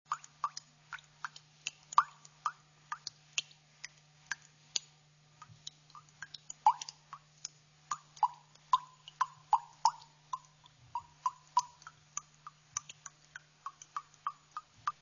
Click to hear the soothing raindrop sound of water being purified.
waterdrops.mp3